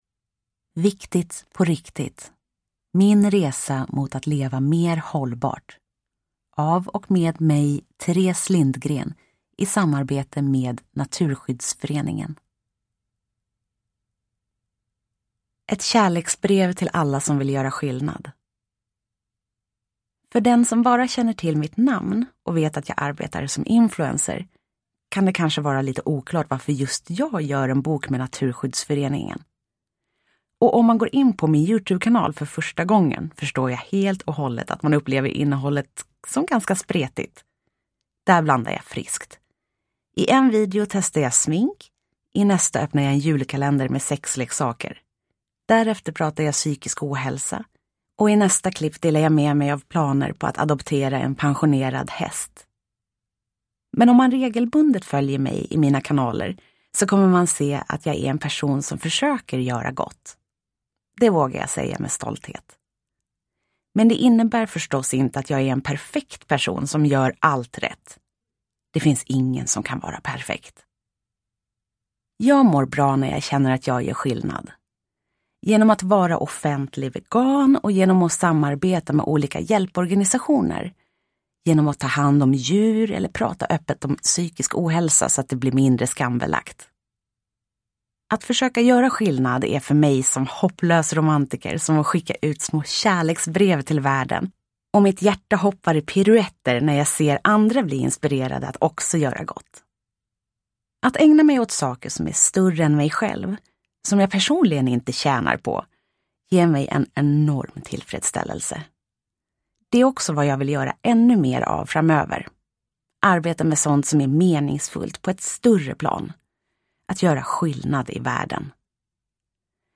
Nedladdningsbar Ljudbok
Ljudbok